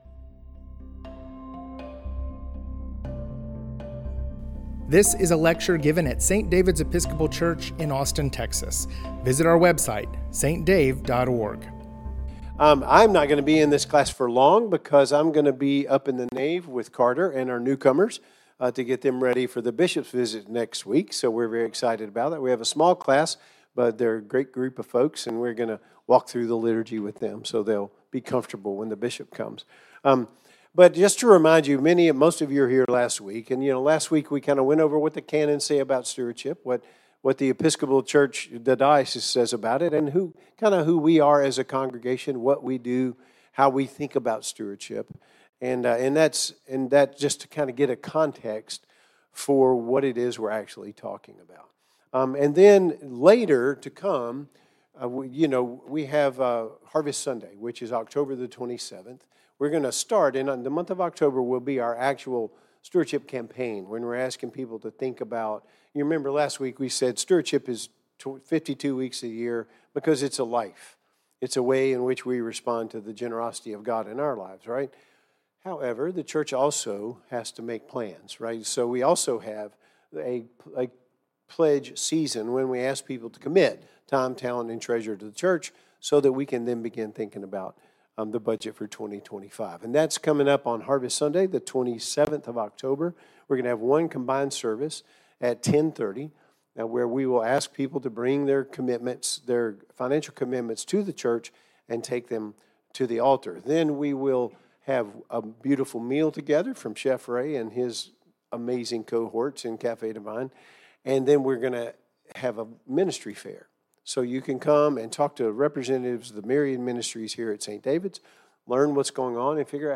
In this second lecture on Stewardship as a Spiritual Practice